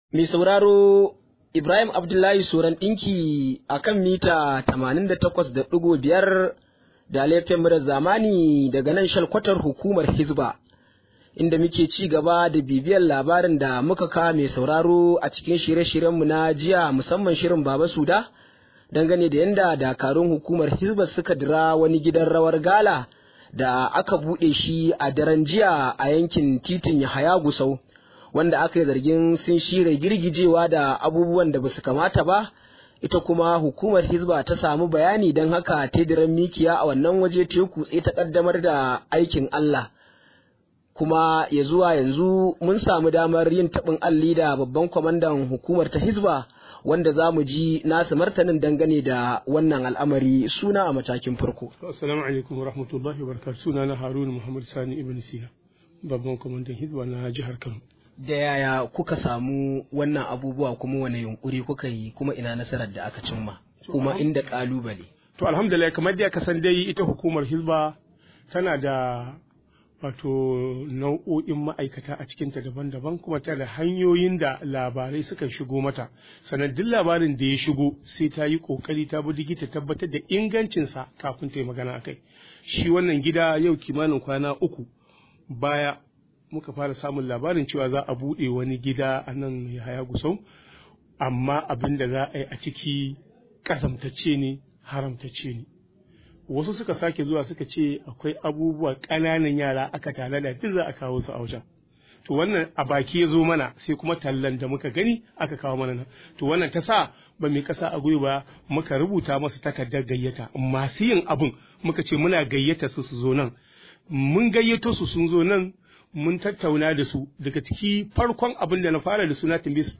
Rahoto: Kazantar da aka yi tunanin za ta faru a gidan gala ba ta faru ba – Hisba
Wakilin namu ya na da cikakken rahoton.